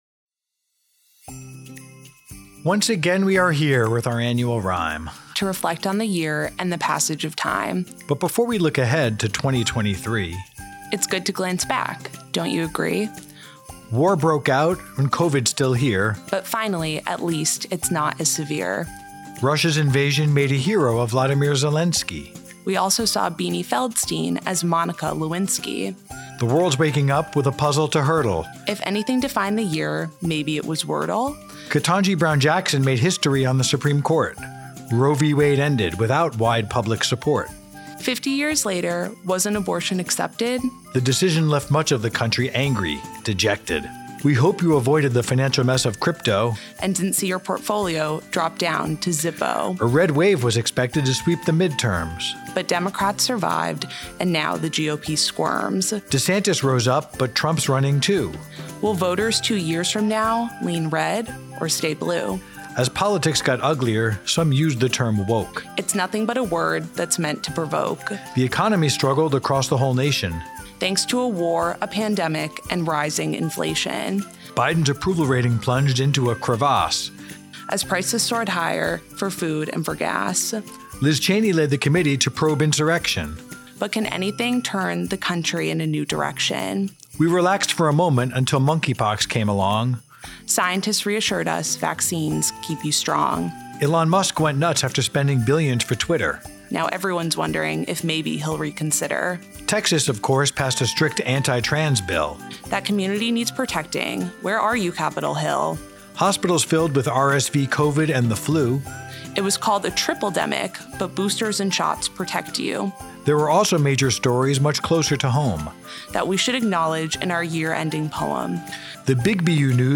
2022 BU Today Holiday Poem